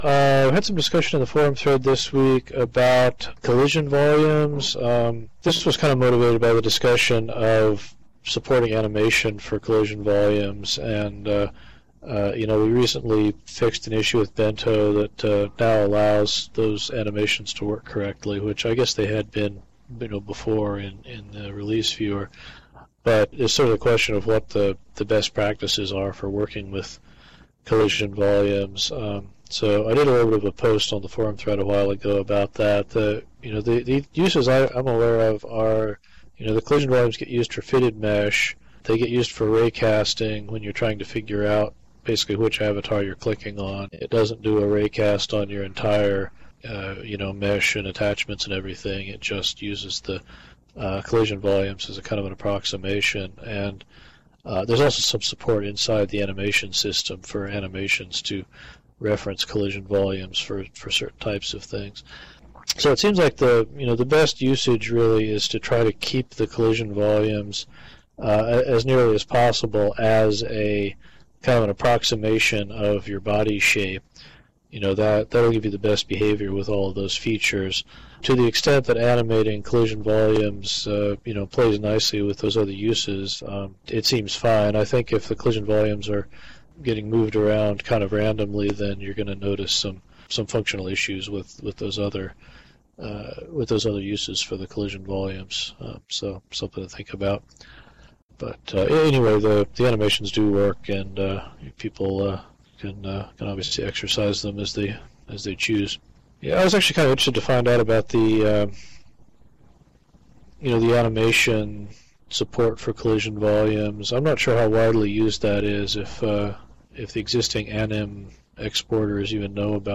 The following notes and audio were taken from the weekly Bento User Group meeting, held on Thursday, September 8th at 13:00 SLT at the the Hippotropolis Campfire Circle.